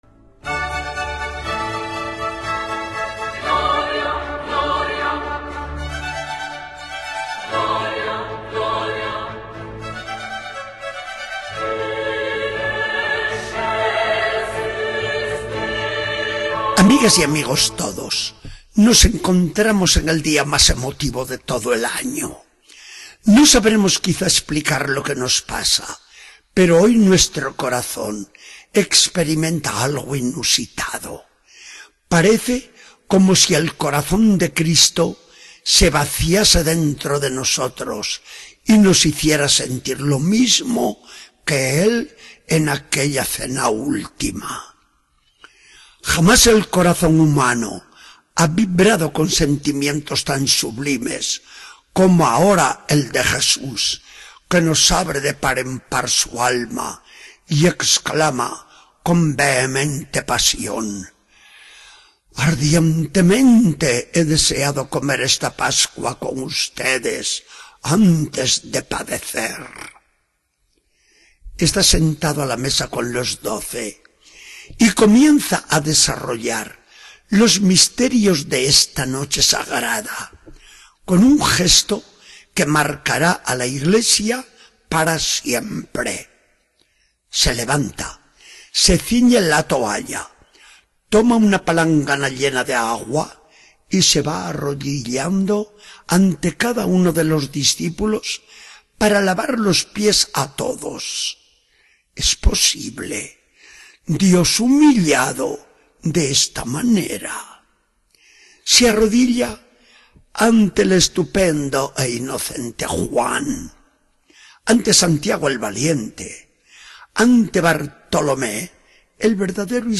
Charla del día 17 de abril de 2014. Del Evangelio según San Juan 13, 1-15.